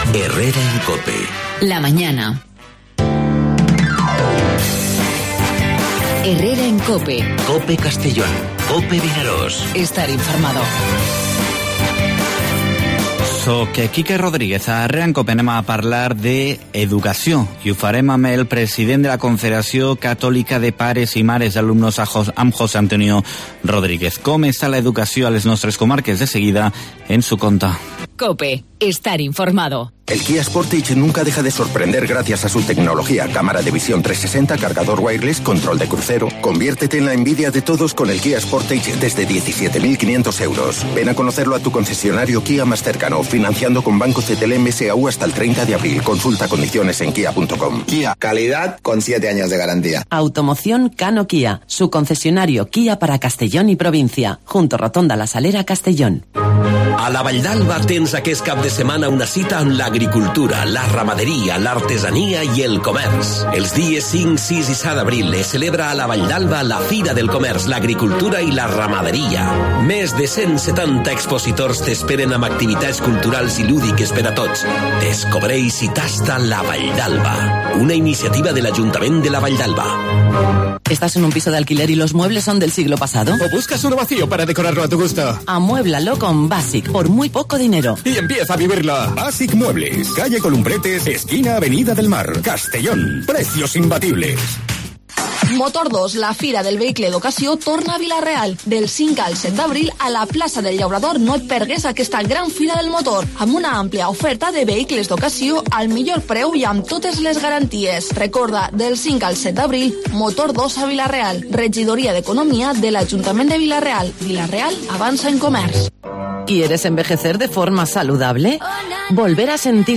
AUDIO: Vinaròs celebra las 'Jornadas de Memoria Histórica', como explica el concejal, Marc Albella, en 'Herrera en COPE'; y las familias reclaman...